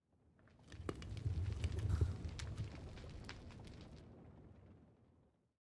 Minecraft Version Minecraft Version latest Latest Release | Latest Snapshot latest / assets / minecraft / sounds / block / smoker / smoker4.ogg Compare With Compare With Latest Release | Latest Snapshot
smoker4.ogg